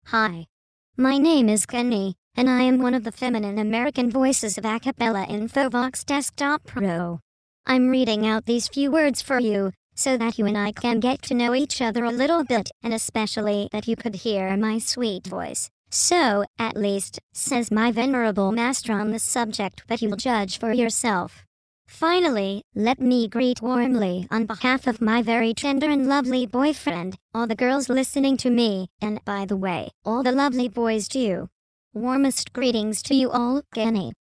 Texte de démonstration lu par Kenny, voix féminine américaine d'Acapela Infovox Desktop Pro
Écouter la démonstration de Kenny, voix féminine américaine d'Acapela Infovox Desktop Pro